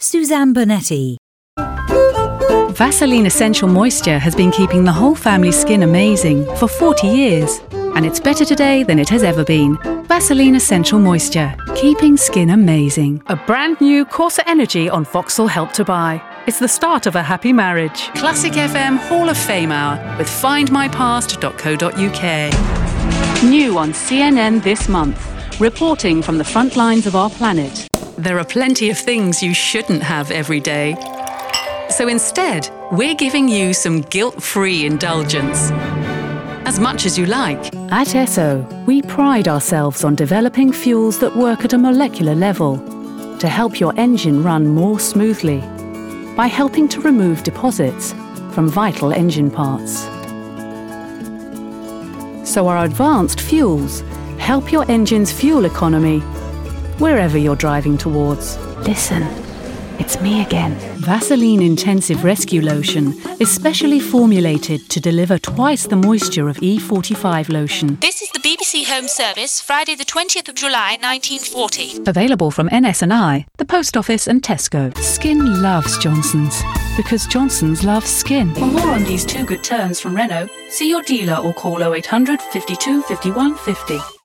Description: Neutral: gentle, natural, assured
Age range: 40s - 50s
Commercial 0:00 / 0:00
RP*